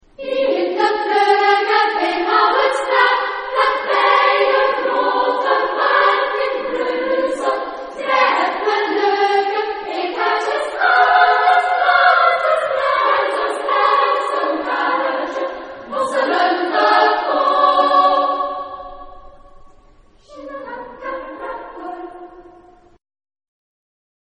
Tonality: F minor ; F major